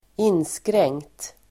Uttal: [²'in:skreng:kt]
inskr0344nkt.mp3